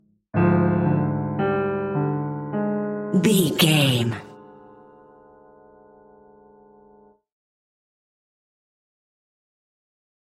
Lo Piano Scene Change Music.
Aeolian/Minor
Slow
tension
ominous
dark
haunting
eerie
melancholic
short stinger
short music instrumental
horror scene change music